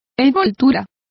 Complete with pronunciation of the translation of envelope.